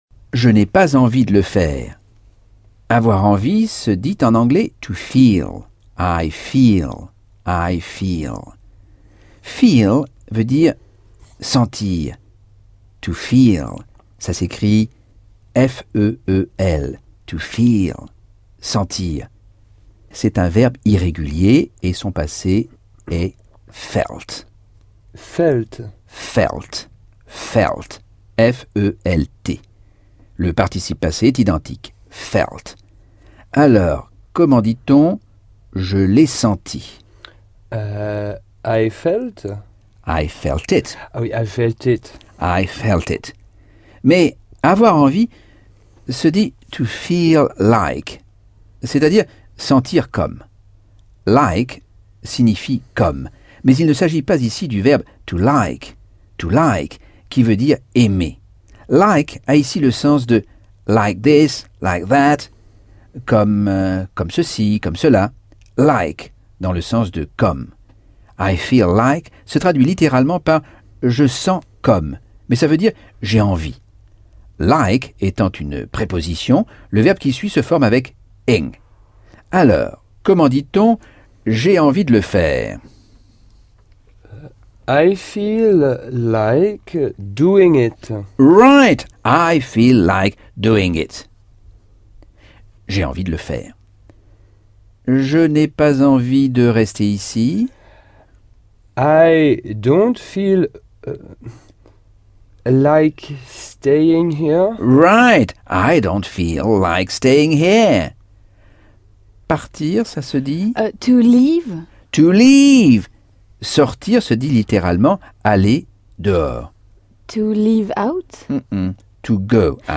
Leçon 9 - Cours audio Anglais par Michel Thomas - Chapitre 10